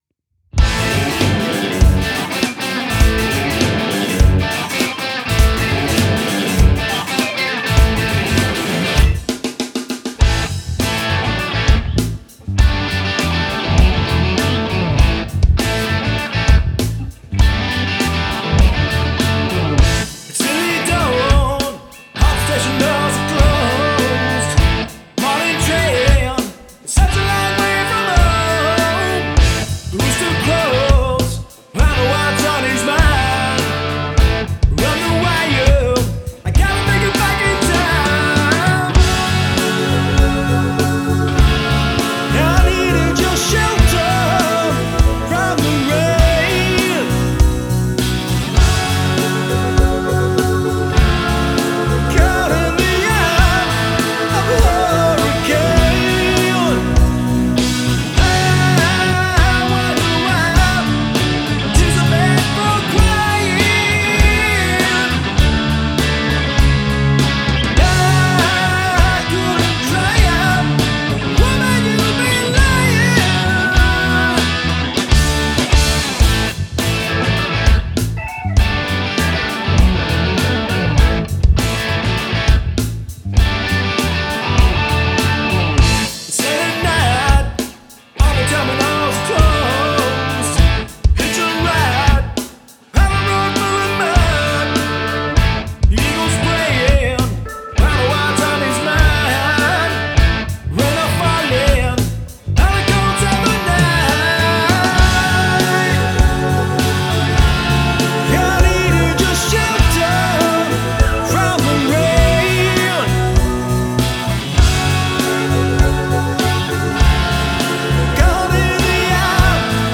Guitars, Vocals & Bass
Hammond Organ, Wurlitzer & Fender Rhodes
blues-edged rock